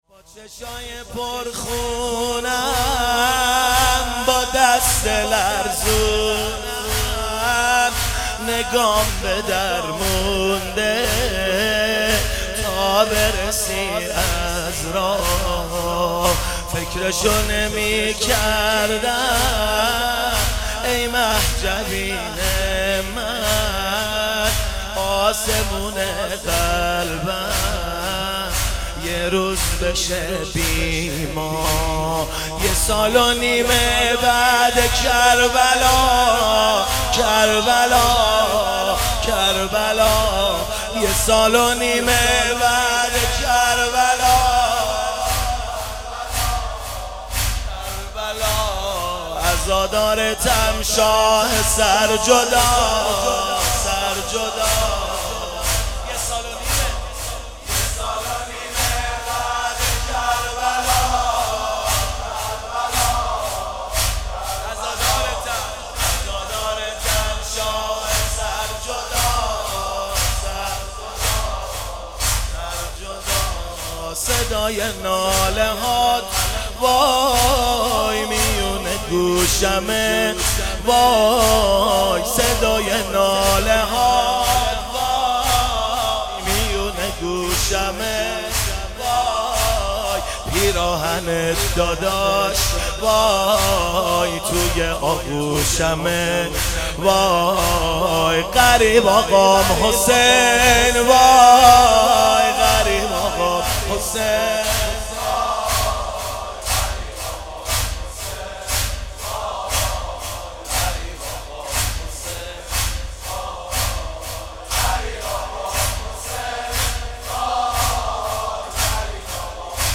شهادت حضرت زینب سلام الله علیها97 - زمینه - با چشم های پر خونم